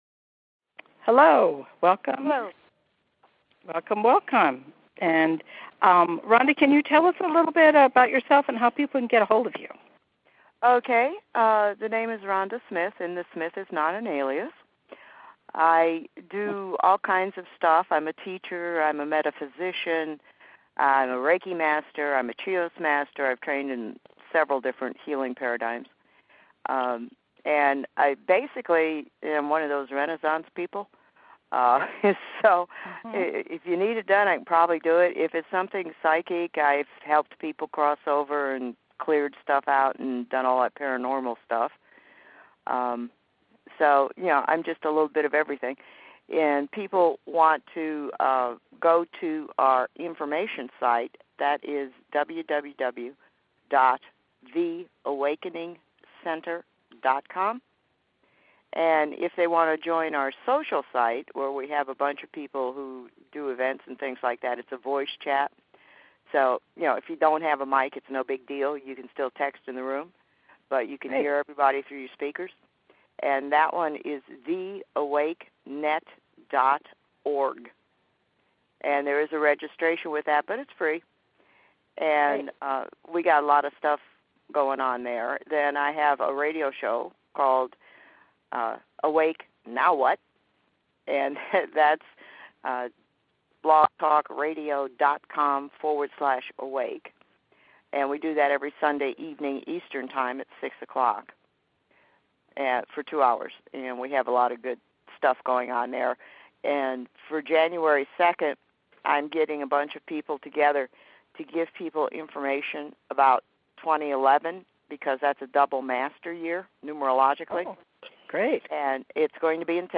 Talk Show Episode, Audio Podcast, Arcturian_Ascension_Process and Courtesy of BBS Radio on , show guests , about , categorized as